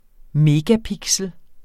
Udtale [ ˈmeːgaˌpigsəl ]